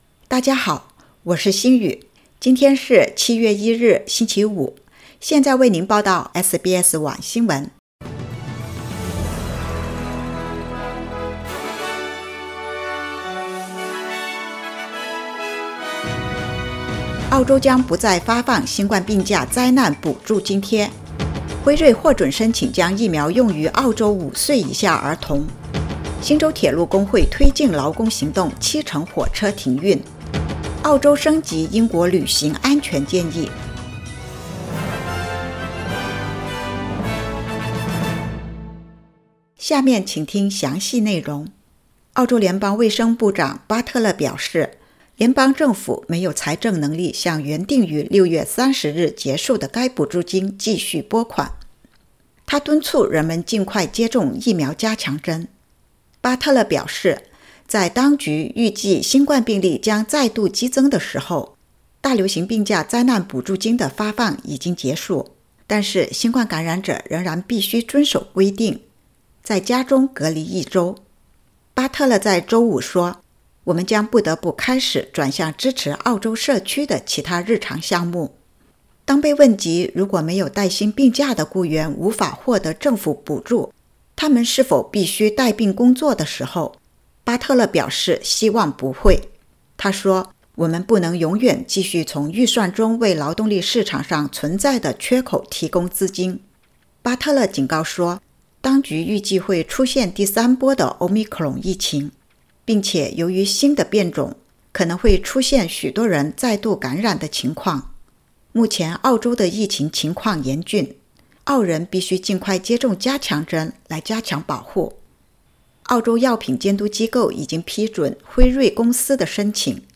SBS晚新闻（2022年7月1日）
SBS Mandarin evening news Source: Getty Images